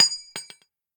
nut_impact_04.ogg